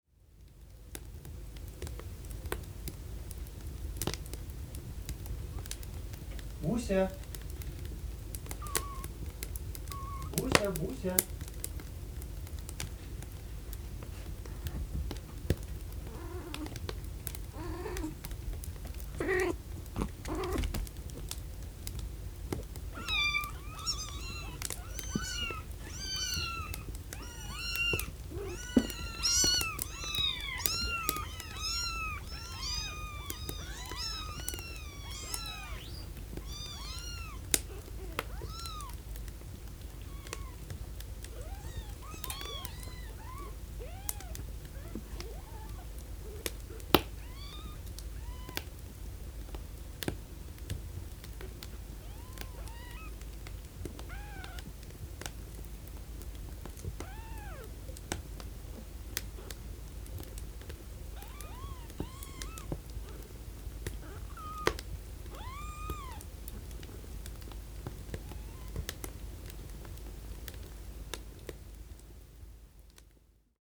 Звуки котят
• Качество: высокое
Писк котят у костра